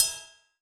Cymbal
Original creative-commons licensed sounds for DJ's and music producers, recorded with high quality studio microphones.
00s-ride-one-shot-f-sharp-key-05-sX2.wav